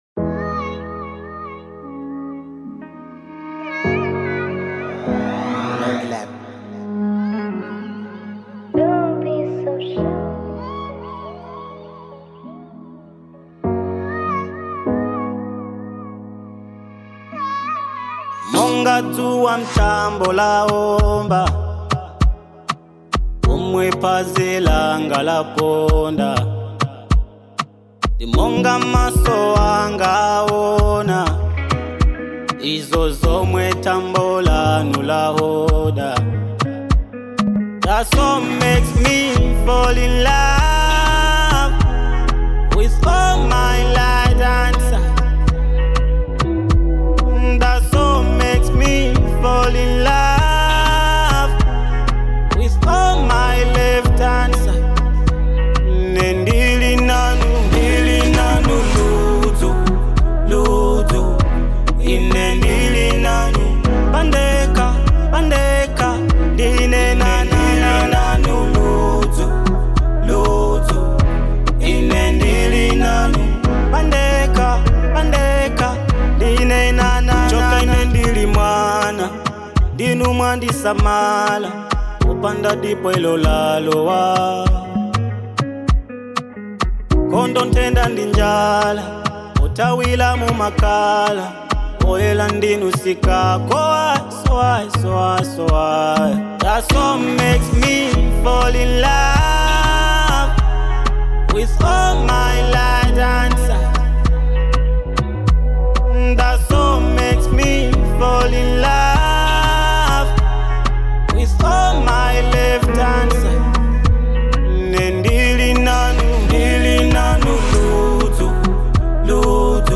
Genre : Afro Dancehall